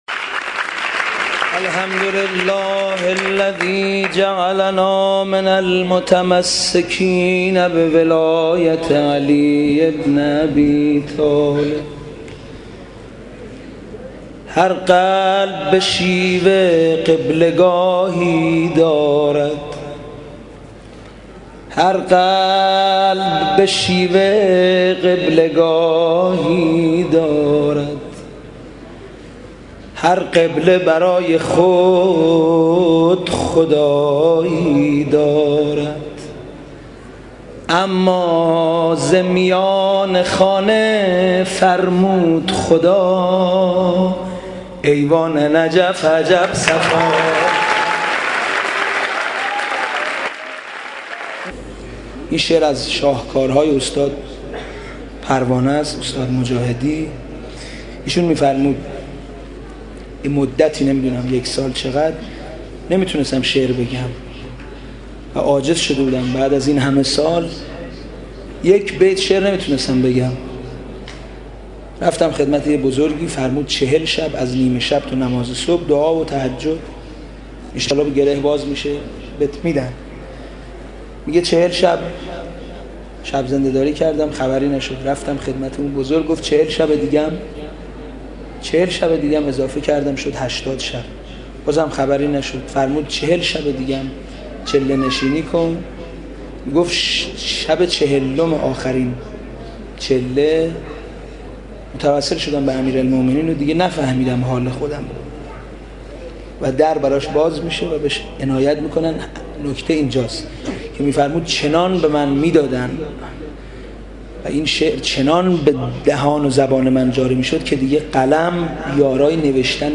مدح امیرالمؤمنین